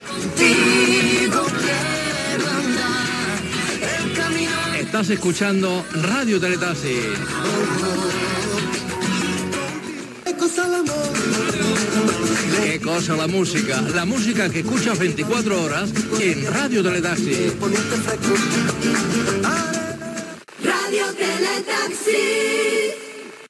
Identificació de la ràdio i indicatiu
Musical
FM